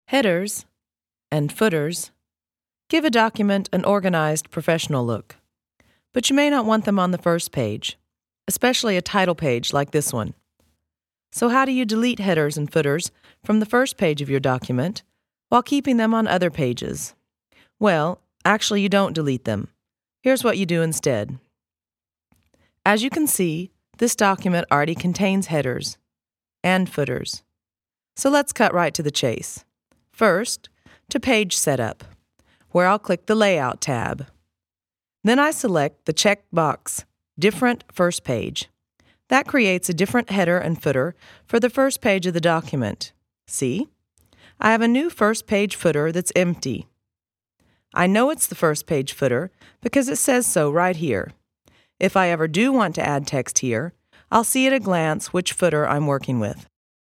native American English speaker. Industrie/ eLearning/Werbung/ promotion
Sprechprobe: Industrie (Muttersprache):